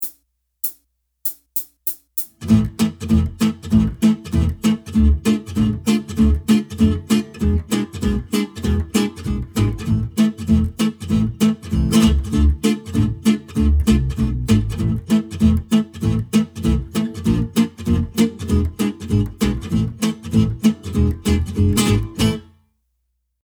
If on the other hand, I was playing with a old style swing player, then I would opt to use the simple triadic voicings of the 1930s.